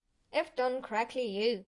基础游戏音效 " PG2 S1